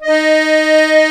D#4 ACCORD-L.wav